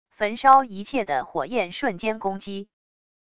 焚烧一切的火焰瞬间攻击wav音频